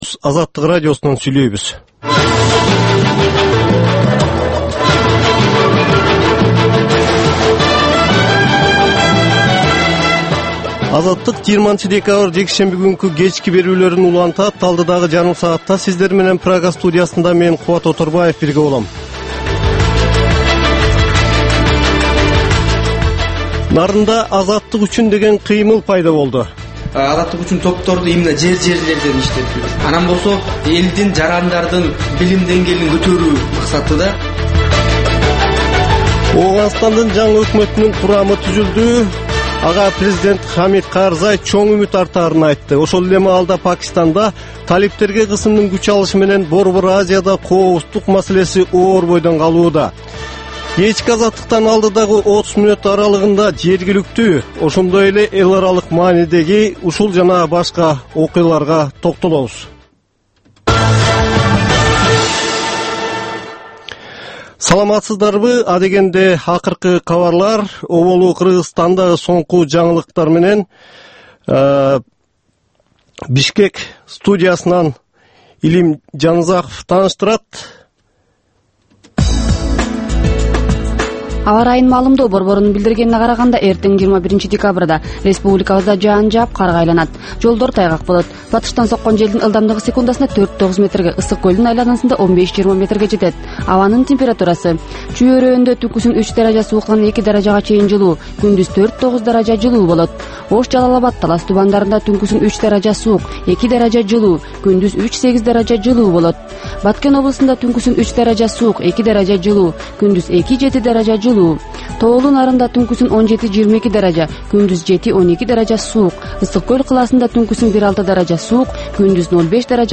Бул кечки үналгы берүү жергиликтүү жана эл аралык кабарлардан, репортаж, маек, баян жана башка берүүлөрдөн турат. "Азаттык үналгысынын" бул кечки берүүсү ар күнү Бишкек убактысы боюнча саат 21:00ден 21:30га чейин обого түз чыгат.